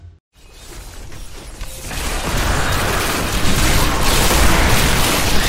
Blast Sound